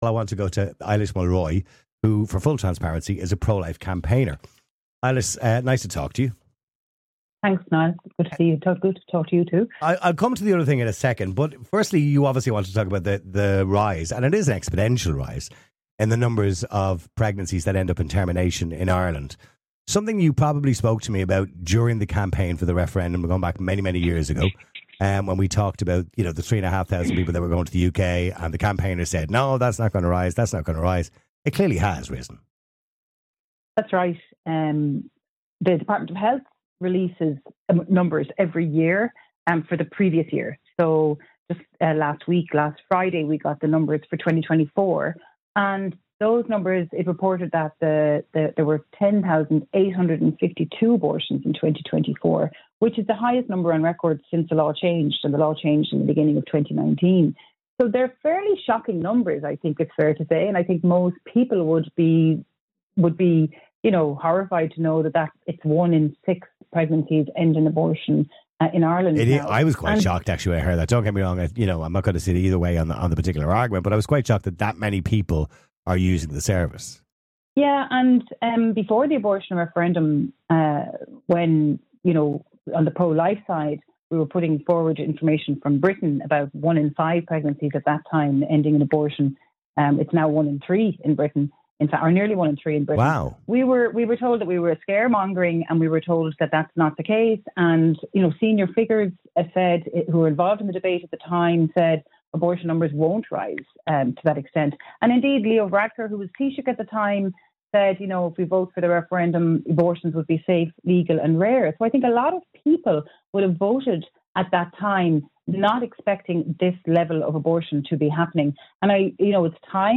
speaking to Niall Boylan on Classic Hits Radio to discuss the rise in abortions in Ireland